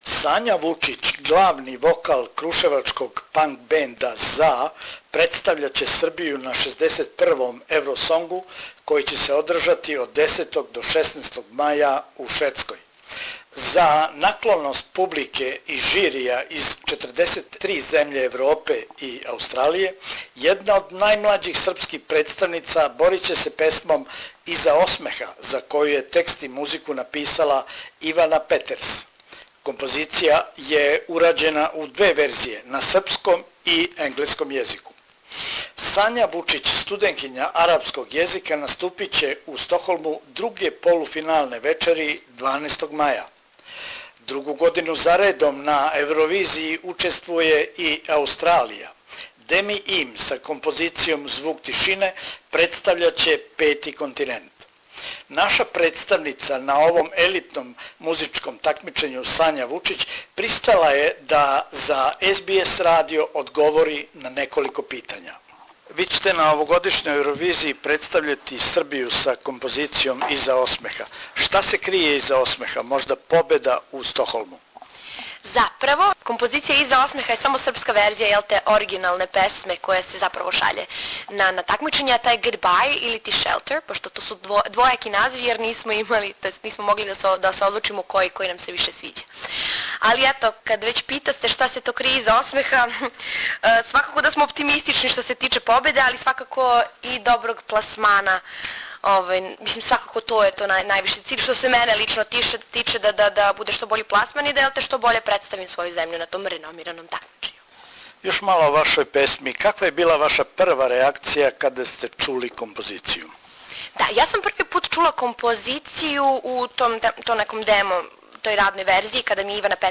Сања Вучић, српска представница на Евровизији 2016, говори за СБС радио о песми којом ће се представити у Стокхолму, о оптимизму с којим путује у Шведску и поручила Србима из Аустралије да је подрже гласовима...